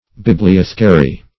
Search Result for " bibliothecary" : The Collaborative International Dictionary of English v.0.48: Bibliothecary \Bib`li*oth"e*ca*ry\, n. [L. bibliothecarius: cf. F. biblioth['e]caire.]
bibliothecary.mp3